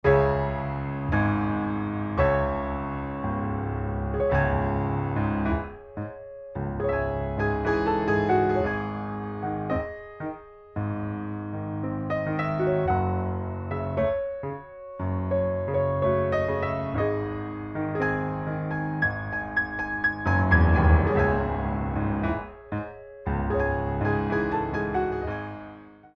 Original Music for Ballet Class
Recorded on a Steinway B at Soundscape
4 Count introduction included for all selections
2/4 - 64 with repeat